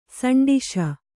♪ saṇḍiśa